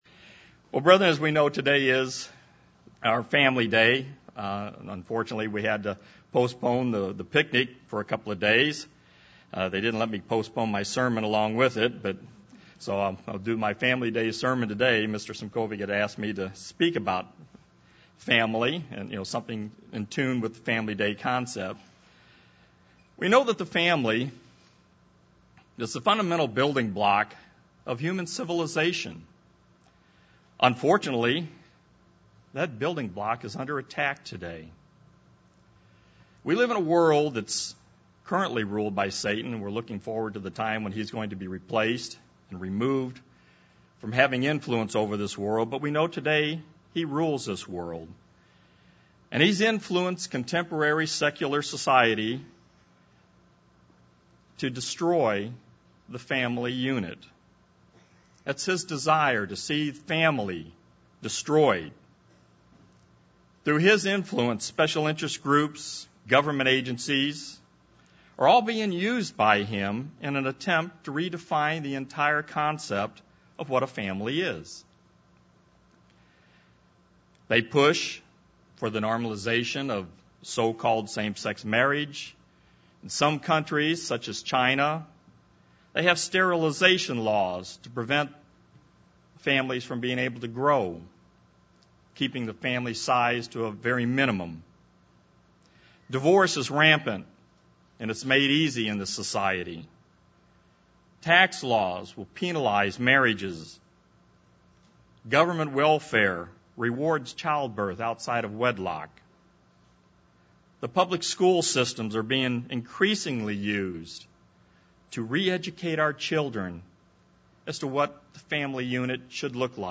This sermon was given at the Branson, Missouri 2014 Feast site.